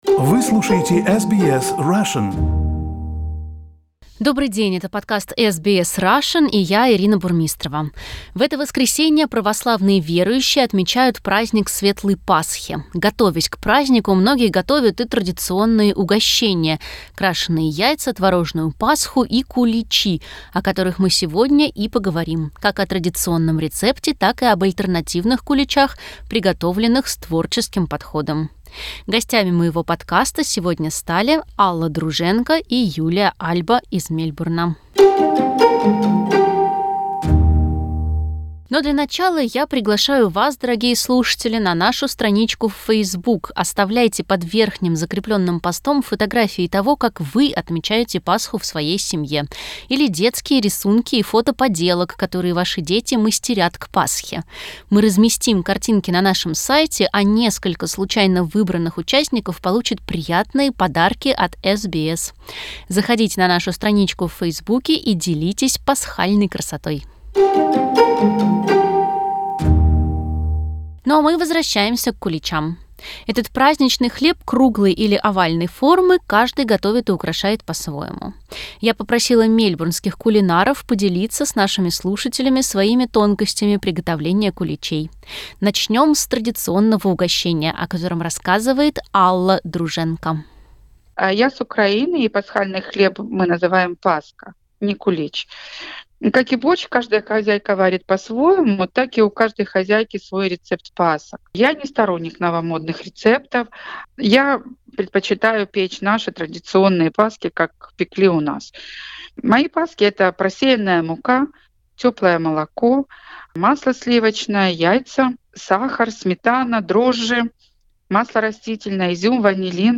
Как приготовить кулич? В этом подкасте кулинары из Мельбурна делятся своими секретами.